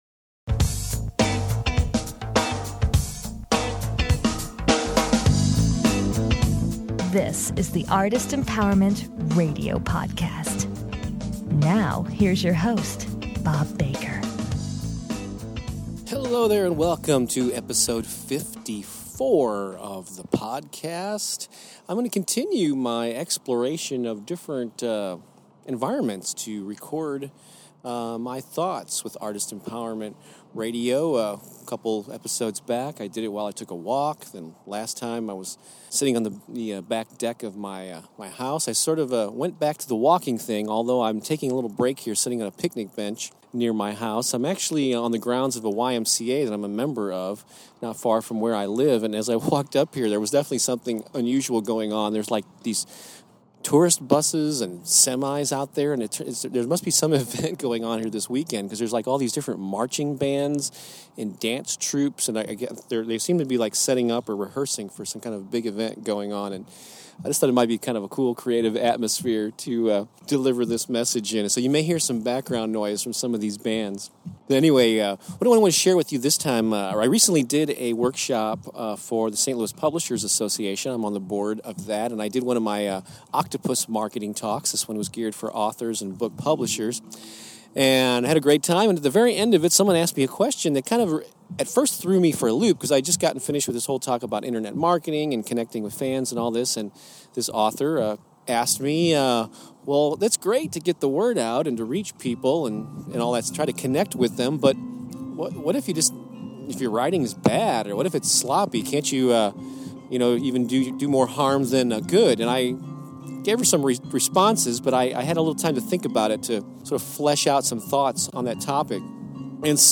On this episode, he also encounters a band of rogue xylophone players.